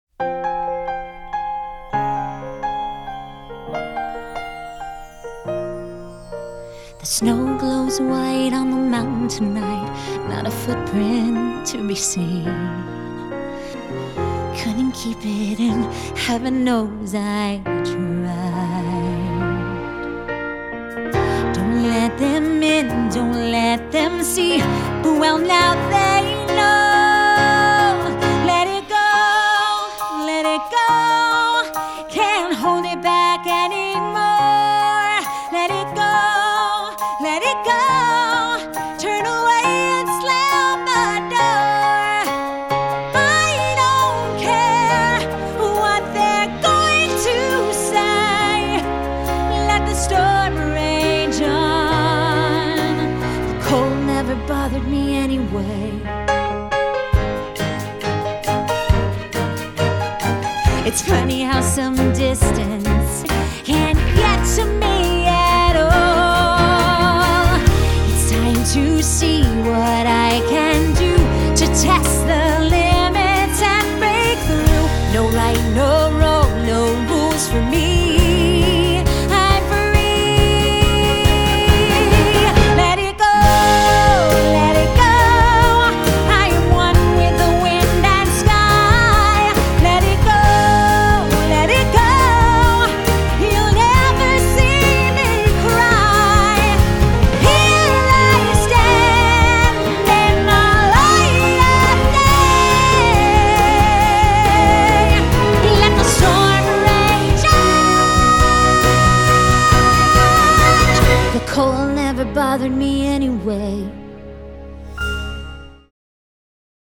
Musiikkilaji: soundtrack.